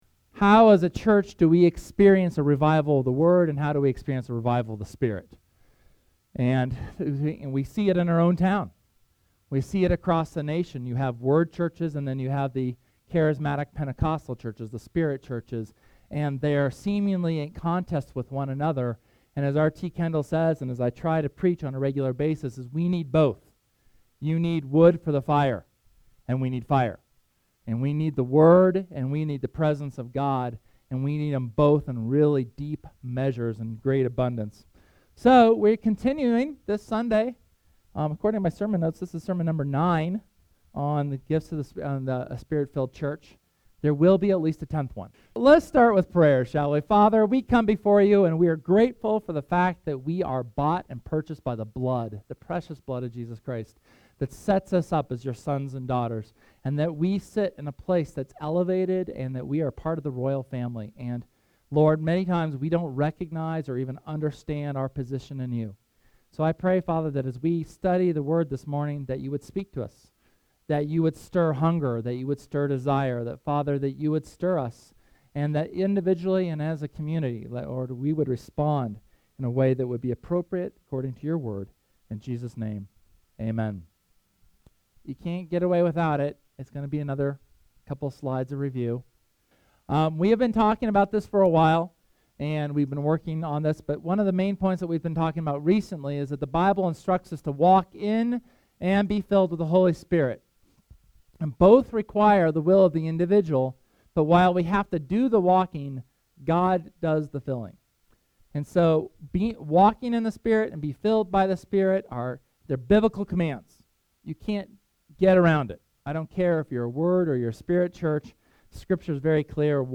SERMON: The Gifts of the Spirit at Work (HS #9)